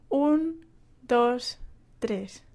描述：一个人用西班牙语数一、二、三的速度比较慢